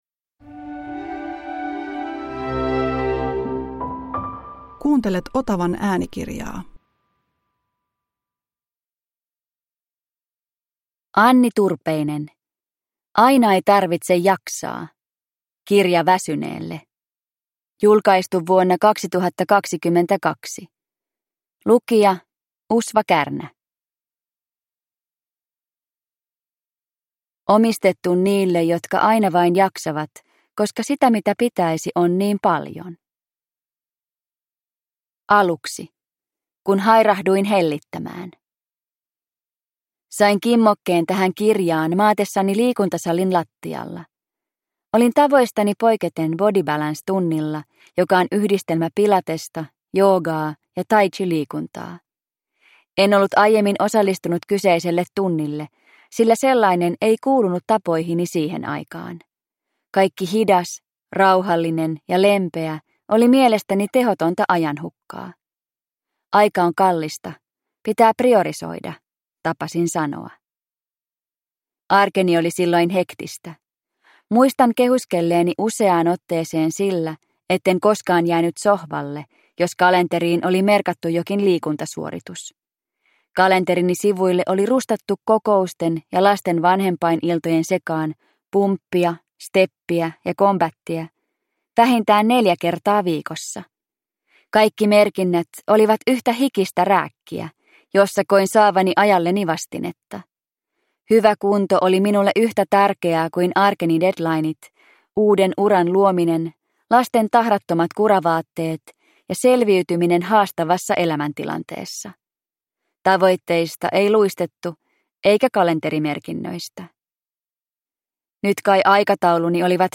Aina ei tarvitse jaksaa – Ljudbok – Laddas ner